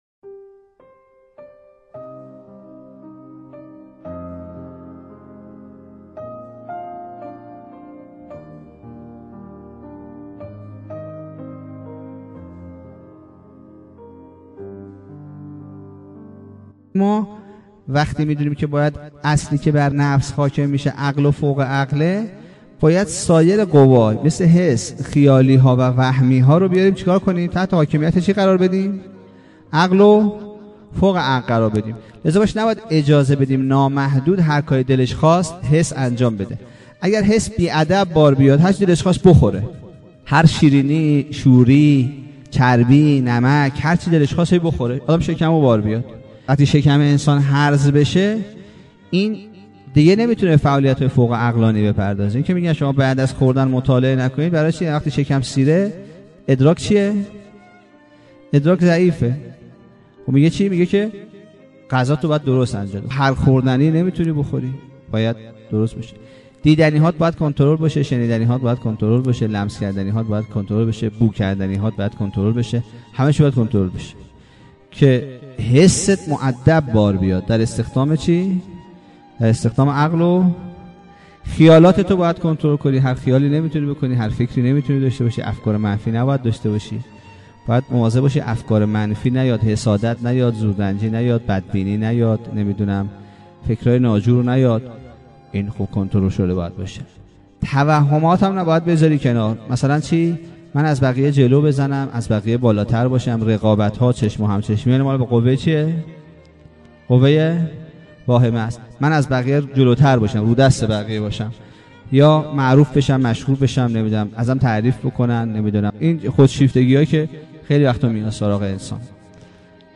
سخنرانی کوتاه